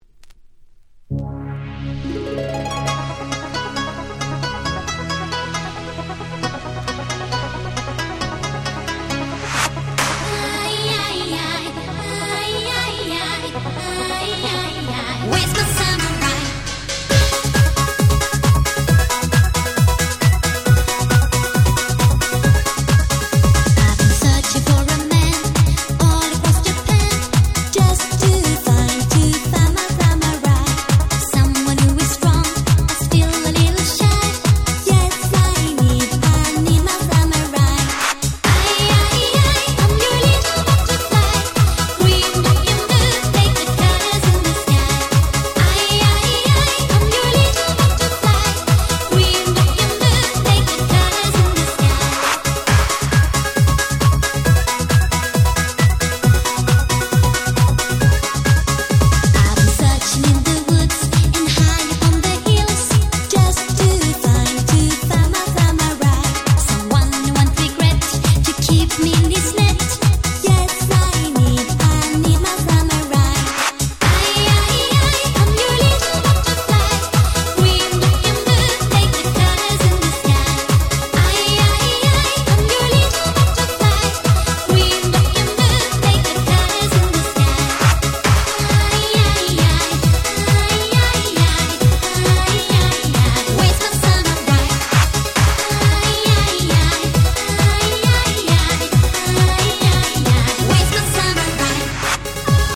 【Media】Vinyl 12'' Single
98' Super Hit Dance Pop !!